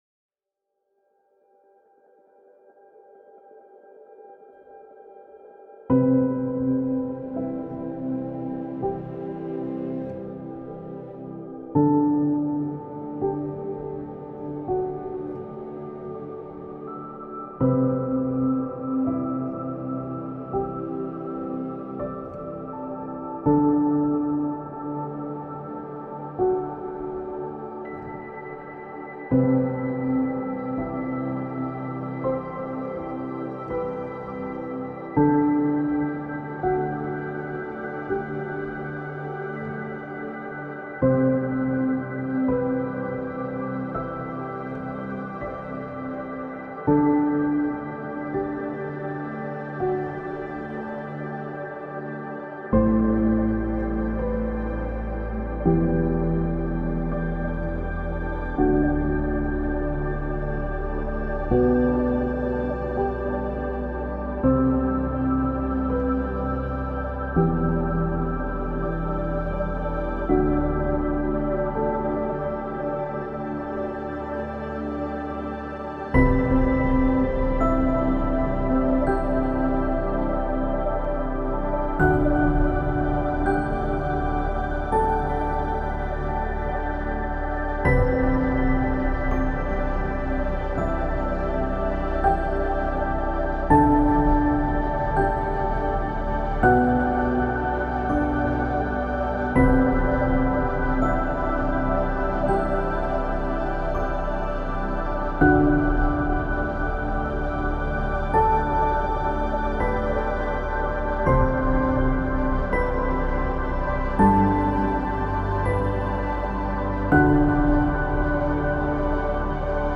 January 25th, 2026 – ESP Sunday Service